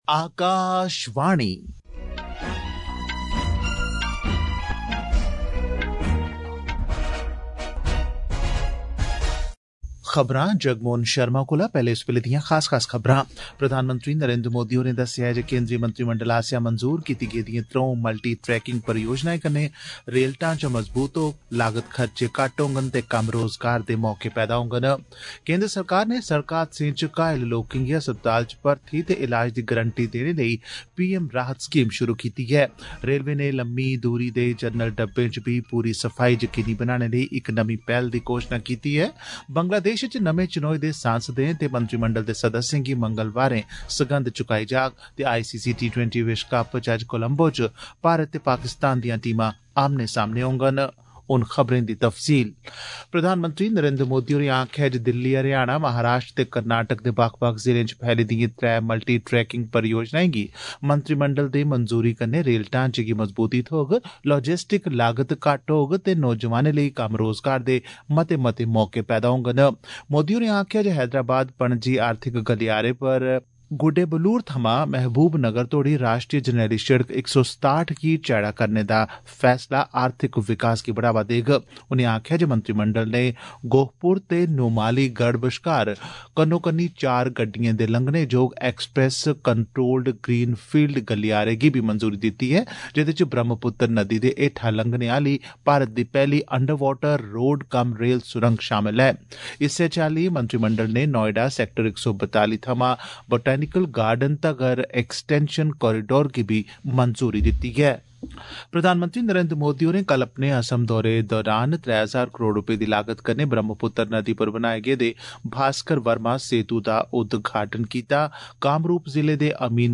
DOGRI-NEWS-BULLETIN-NSD-1.mp3